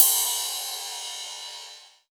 Index of /VEE/VEE2 Cymbals/VEE2 Rides
VEE2 Ride 38.wav